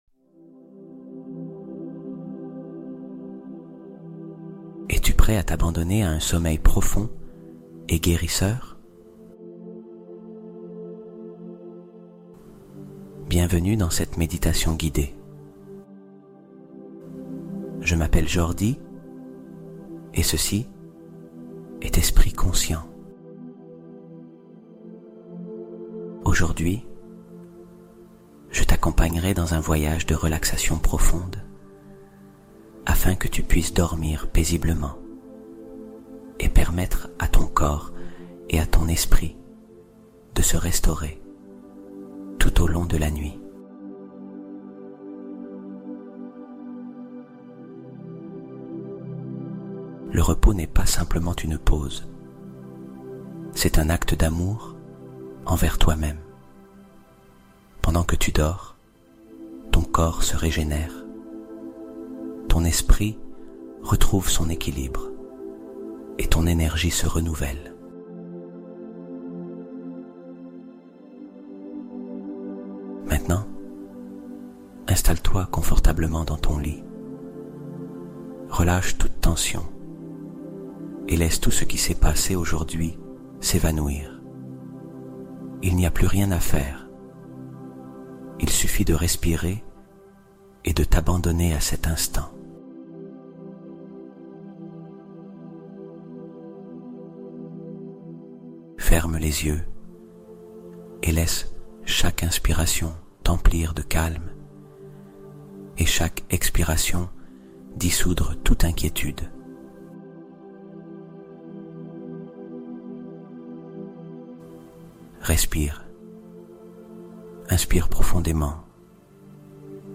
Sommeil Réparateur : Méditation guidée pour une régénération totale